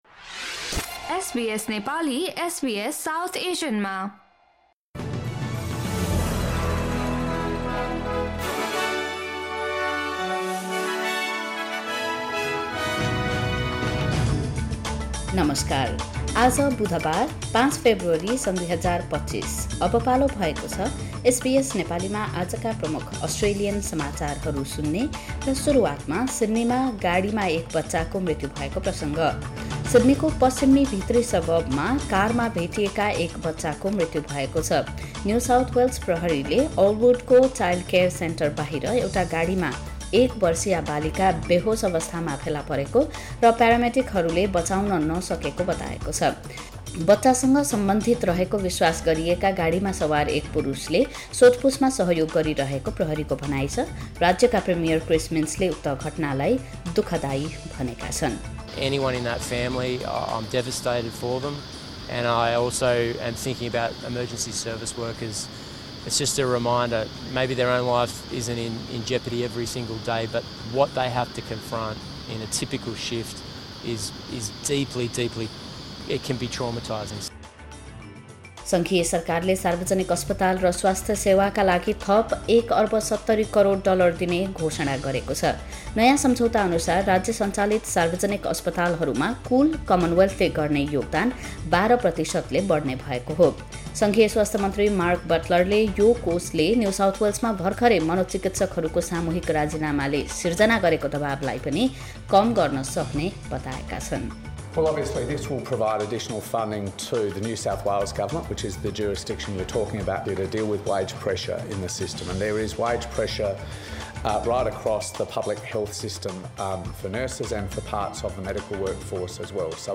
SBS Nepali Australian News Headlines: Wednesday, 5 February 2025